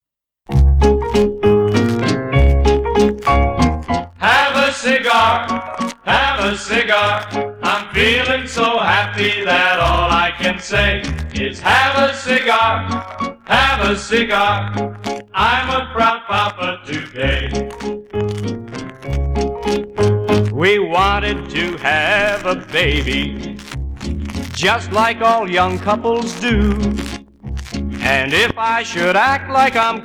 Singing Call (two instrumentals)